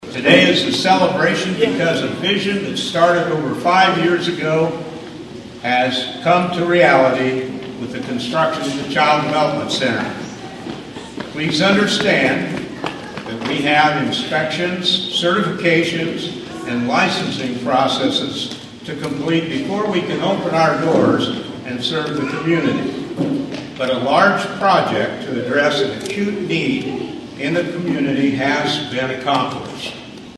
(Griswold) A Grand Opening Ceremony was held for the Lakin Foundation Child Development Center of Griswold on Saturday.
The communities support and involvement in this project was echoed by all who spoke at the ceremony.